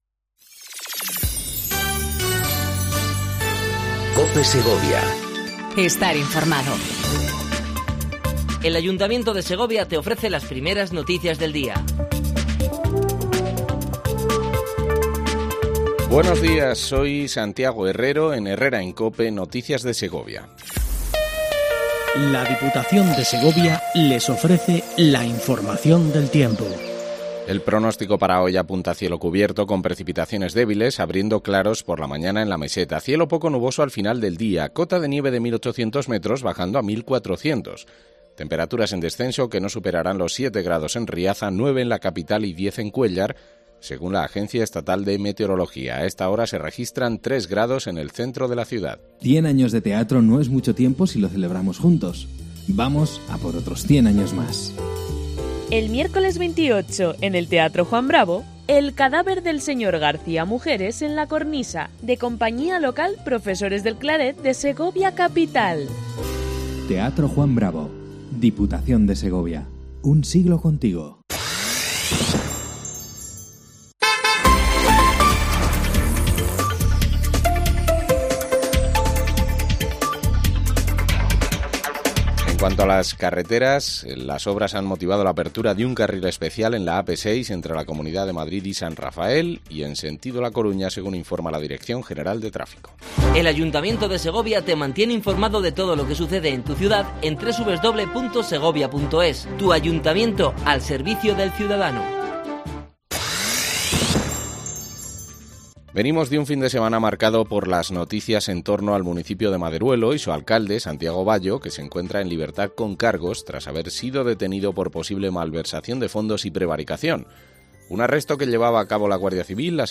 INFORMATIVO 07:55 COPE SEGOVIA 26/11/18
AUDIO: Primer informativo local en cope segovia